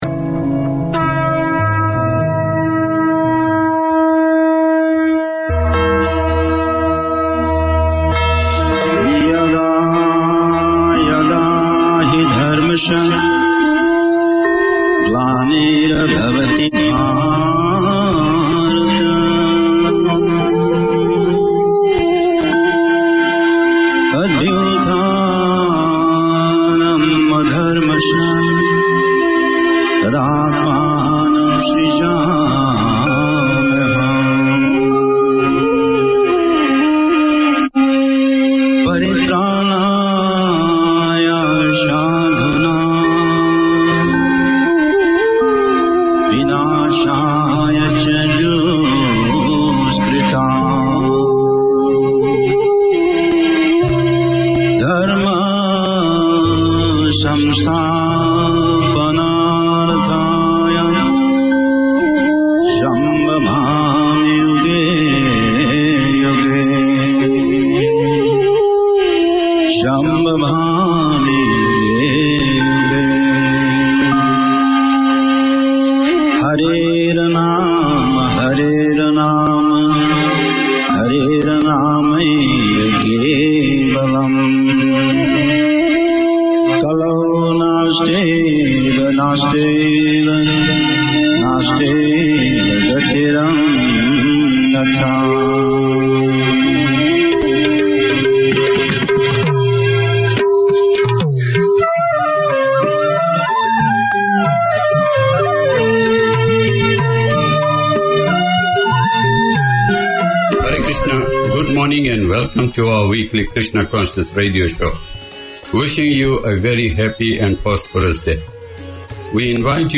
Each week we discuss the devotional teachings of Lord Sri Krishna based on Srimad Bhagwad Gita. We examine the issues people face in the present age of Kali Yuga and its solutions based on Srimad Bhagwad. The talk is highlighted with the playing of bhjans.